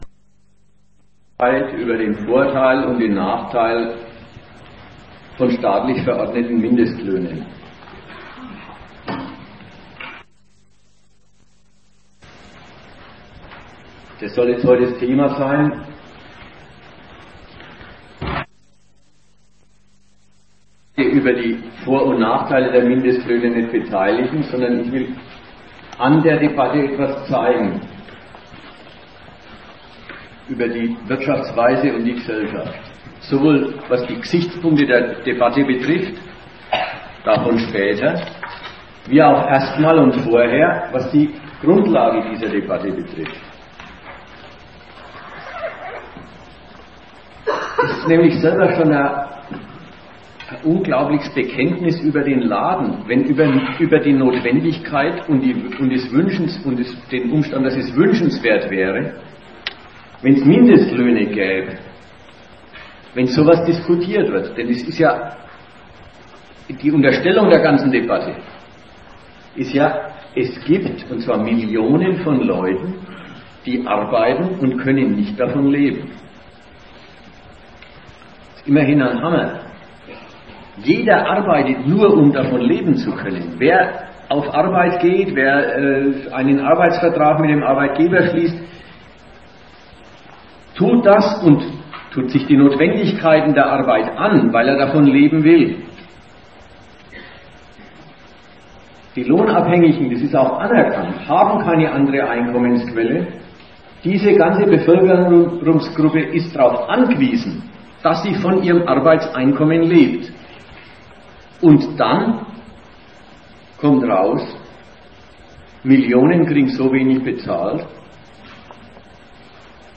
Die Aufnahme ist akustisch von mäßiger Qualität. Insbesondere sind die Beiträge aus dem Publikum schwer bis unverständlich und deshalb bis auf wenige Ausnahmen geschnitten. Da die Aufnahme mit Kassettenrekorder erfolgte, gibt es leider auch Lücken.